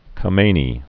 (kə-mānē, ä-, ämā-nē), Ayatollah (Seyyed) Ali Born 1939.